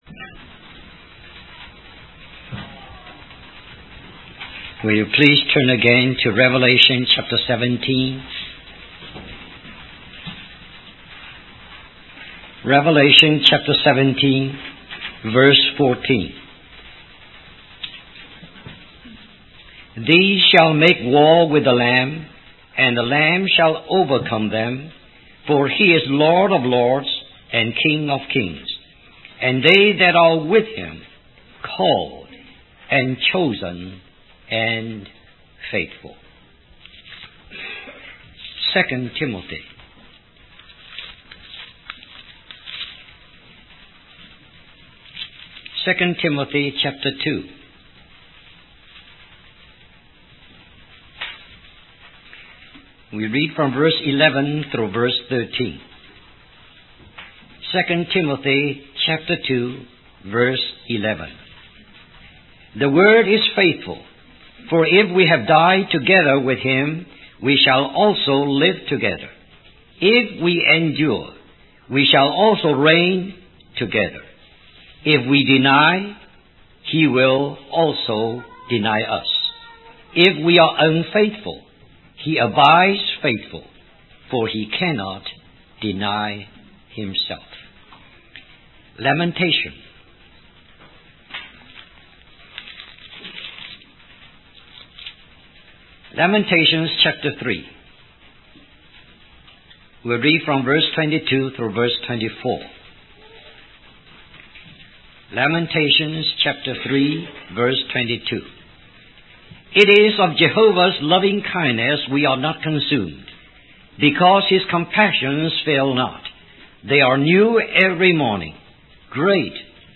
In this sermon, the preacher emphasizes the importance of faithfulness to God. He refers to the letters to the churches in Revelation, specifically the letter to the church in Smyrna, which encourages believers to remain faithful despite tribulation.